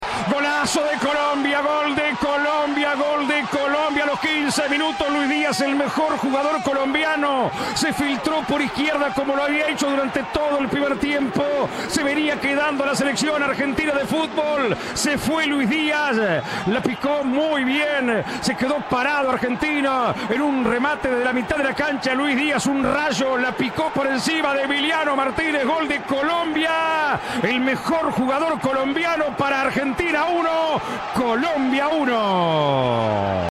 Vibrá con los relatos de los goles y del penal del triunfo